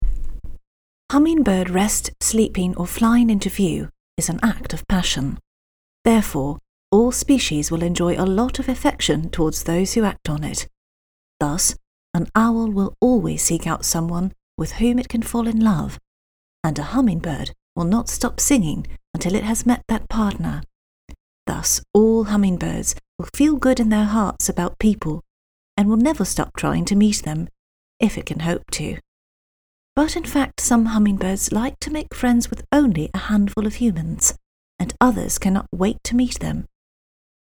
Hummingbird Nature Documentary UK